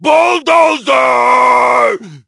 bull_ulti_vo_01.ogg